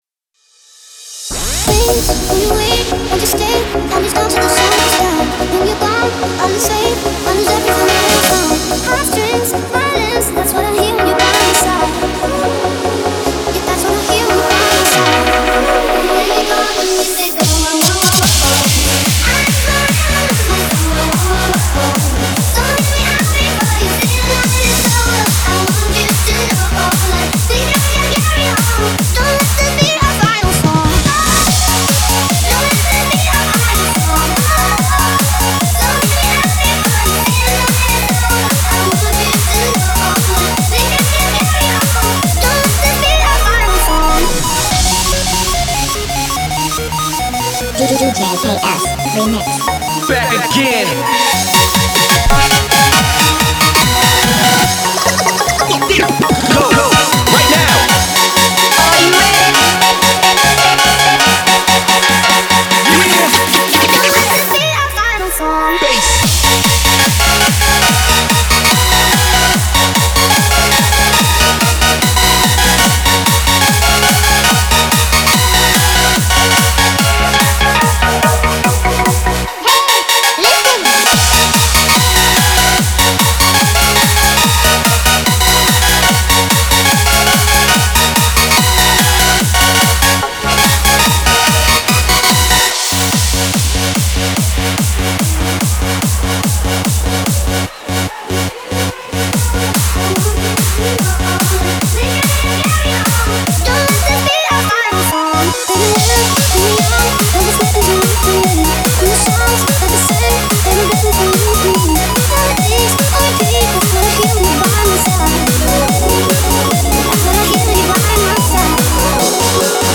a Hands Up song